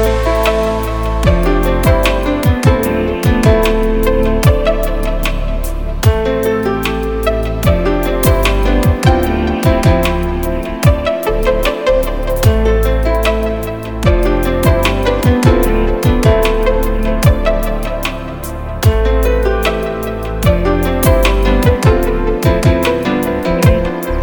Category: Electro RIngtones